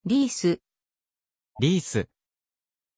Aussprache von Reese
pronunciation-reese-ja.mp3